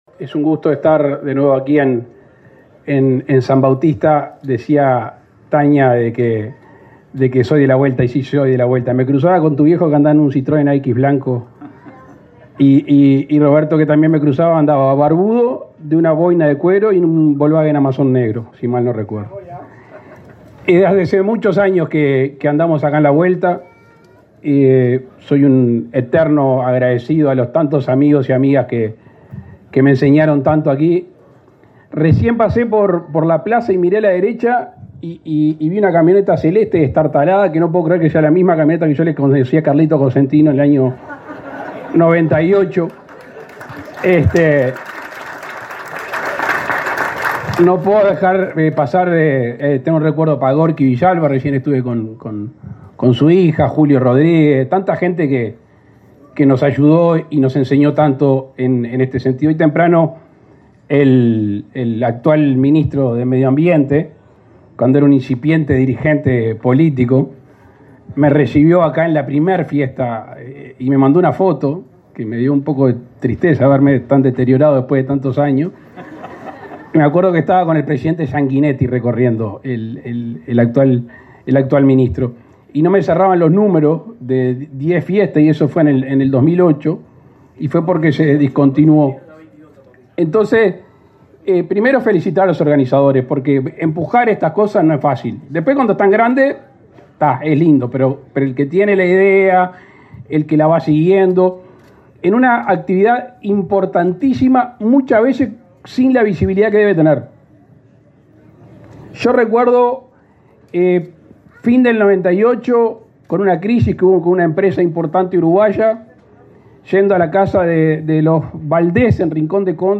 Palabras del presidente Luis Lacalle Pou
El presidente Luis Lacalle Pou encabezó en San Bautista, Canelones, la inauguración de la Expo Avícola 2022.